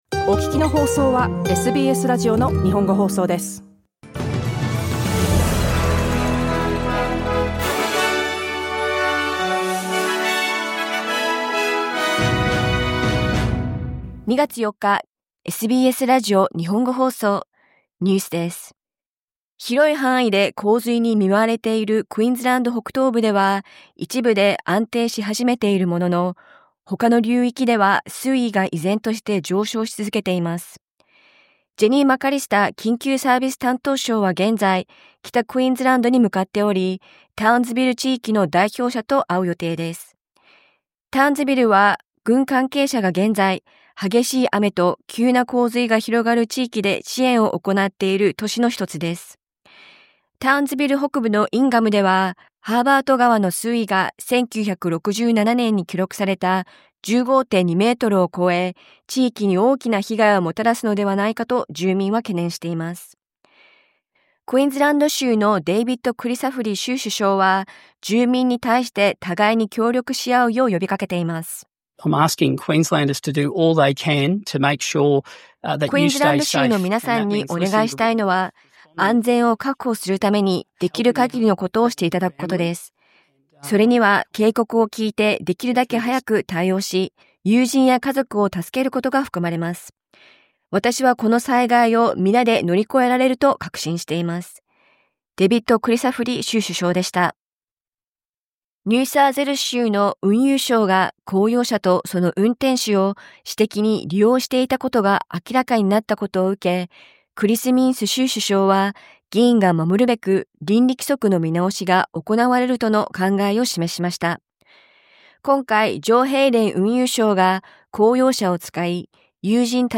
アメリカのトランプ大統領 が、メキシコとカナダへ4日から課すとしていた関税措置について、 1か月停止することを発表しました。午後１時から放送されたラジオ番組のニュース部分をお届けします。